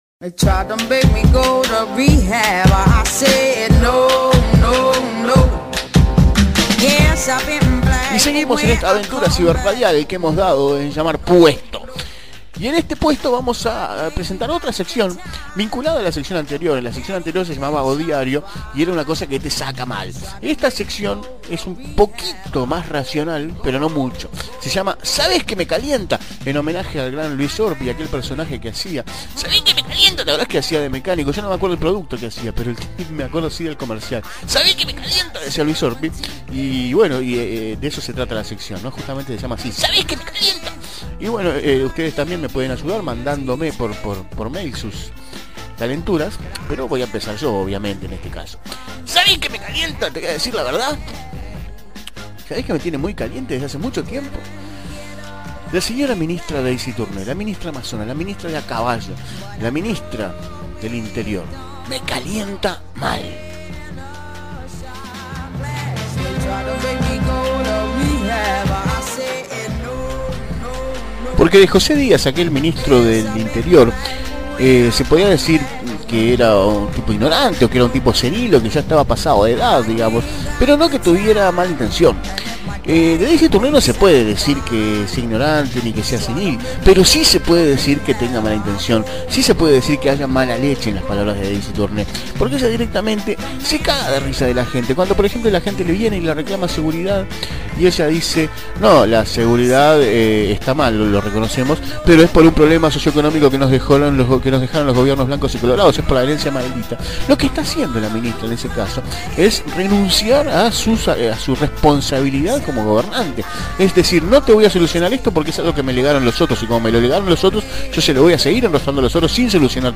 el programa de radio online